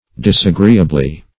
Disagreeably \Dis`a*gree"a*bly\, adv.